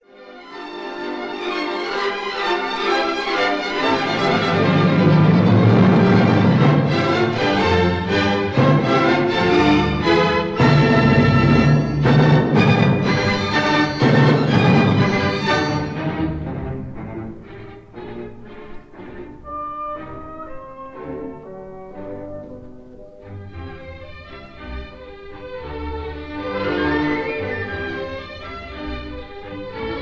live recording made October 1948